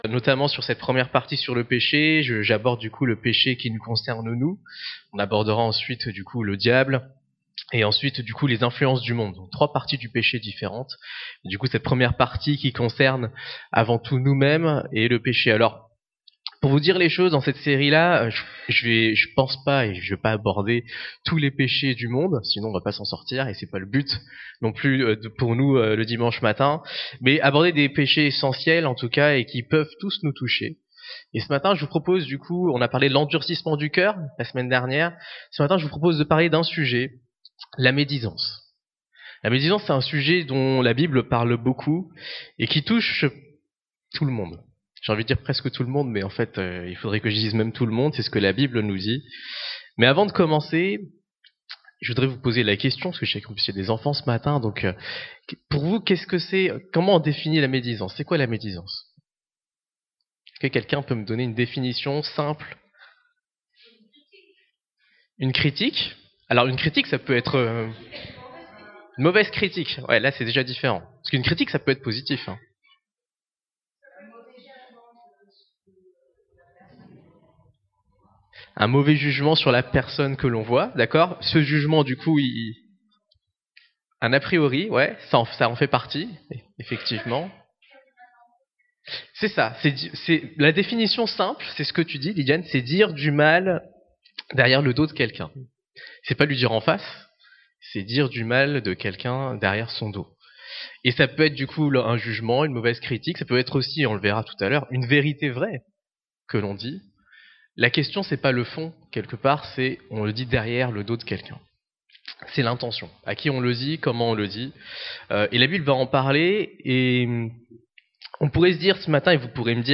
2025 La médisance Prédicateur